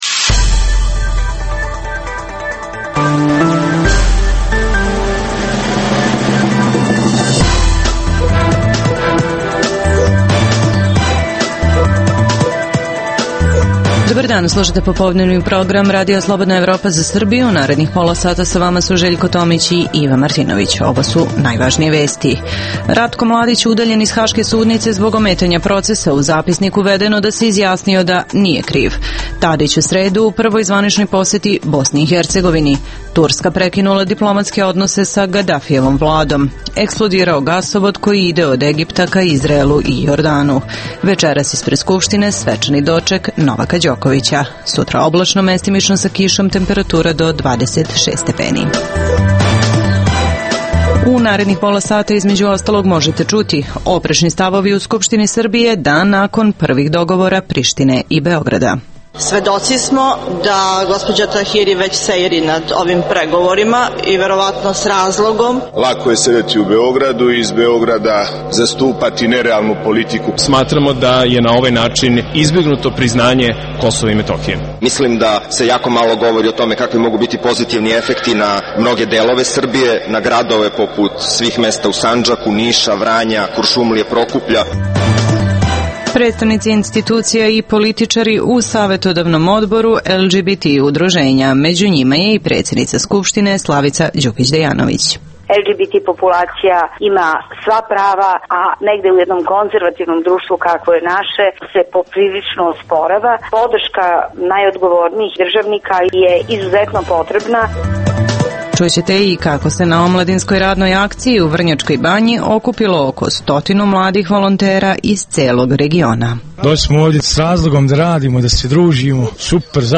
Tim povodom za RSE govori jedna od članica, predsednica Skupštine Srbije Slavica Đukić Dejanović. - Čućete i kako se na Omladinskoj radnoj akciji u Vrnjačkoj Banji okuplo oko 100 mladih volontera iz bivših jugoslovenskih republika.